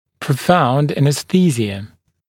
[prə’faund ˌænɪs’θiːzɪə][прэ’фаунд ˌэнис’си:зиэ]глубокая анестезия